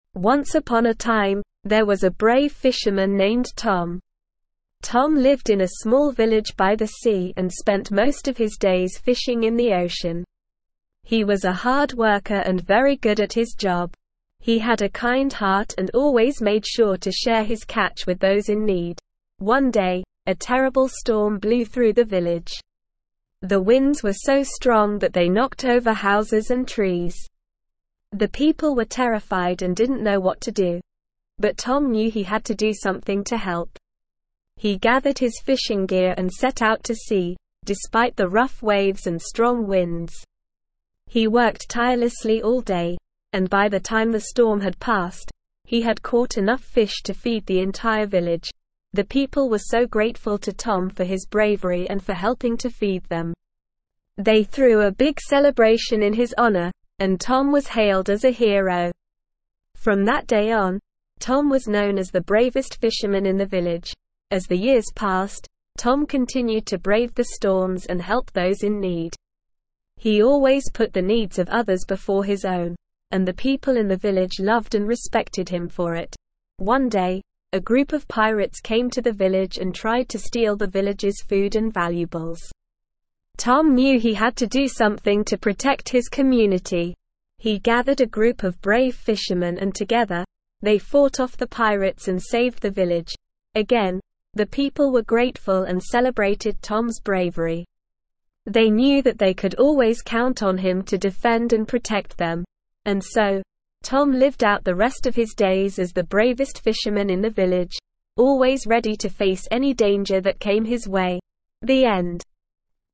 Slow
ESL-Short-Stories-for-Kids-SLOW-reading-Tom-the-Brave-Fisherman.mp3